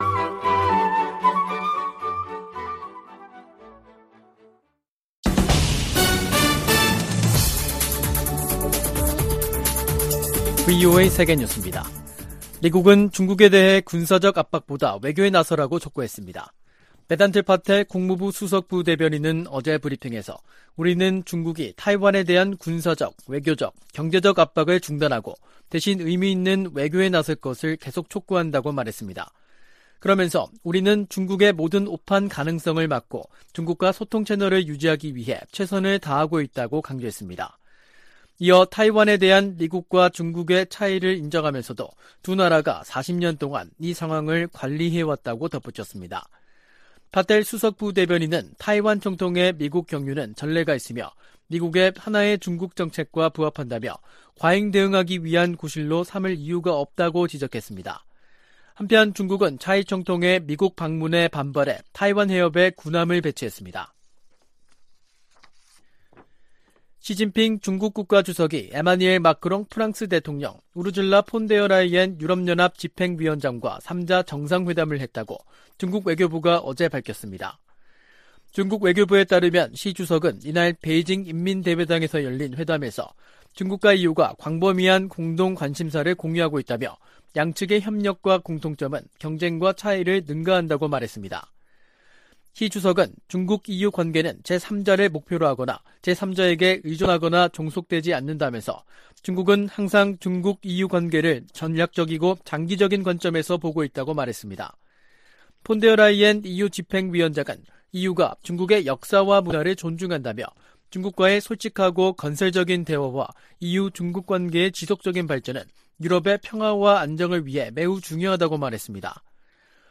VOA 한국어 간판 뉴스 프로그램 '뉴스 투데이', 2023년 4월 7일 3부 방송입니다. 미한일 북핵 수석대표들이 7일 서울에서 공동성명을 발표하고 유엔 회원국들에 안보리 결의를 완전히 이행할 것을 촉구했습니다. 백악관은 북한이 대화에 복귀하도록 중국이 영향력을 발휘할 것을 촉구했습니다. 북한 정보기술 노동자와 화가 등이 여전히 중국과 동남아시아에서 외화벌이를 하고 있다고 유엔이 지적했습니다.